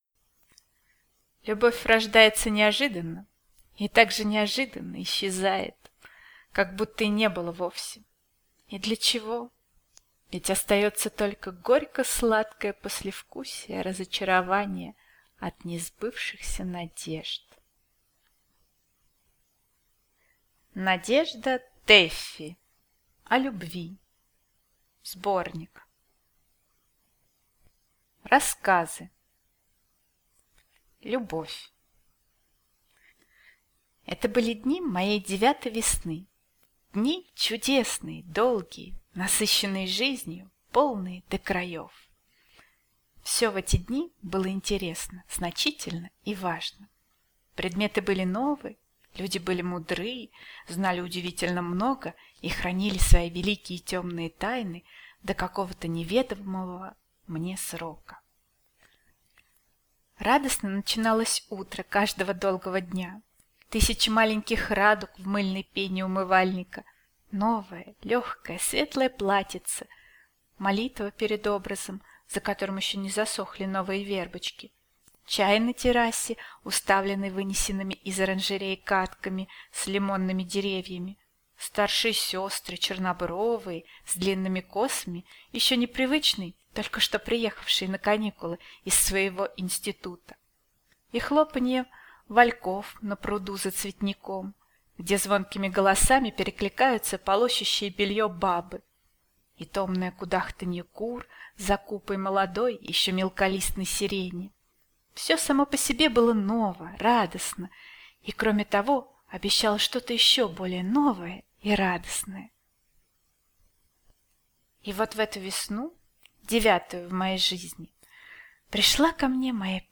Аудиокнига О любви (сборник) | Библиотека аудиокниг